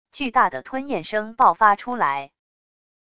巨大的吞咽声爆发出来wav音频